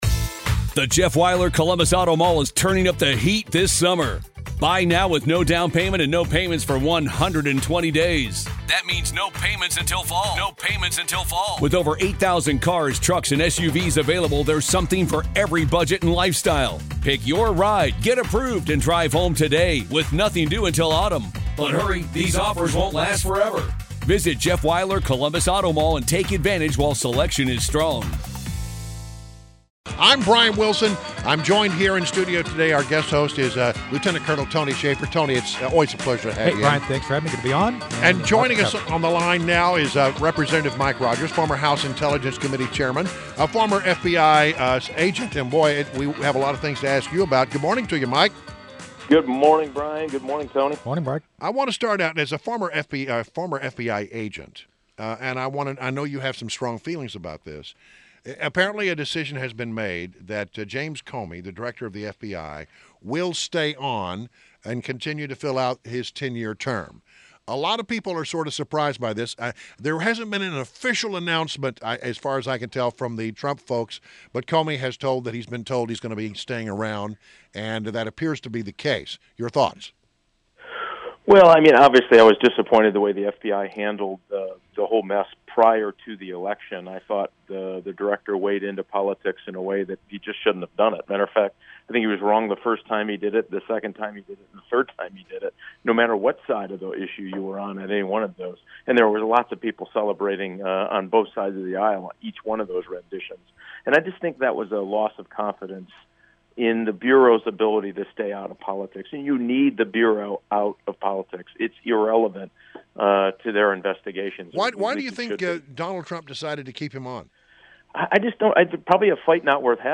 INTERVIEW – REP. MIKE ROGERS – Former House Intelligence Chairman and former FBI Special Agent – discussed FBI Director Comey staying on and new CIA chief Pompeo confirmed this week.